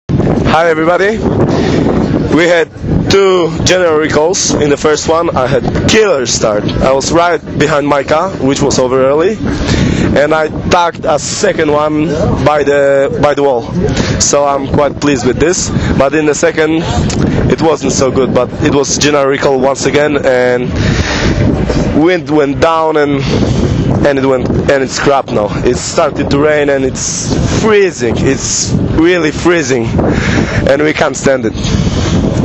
More interviews… – Formula Windsurfing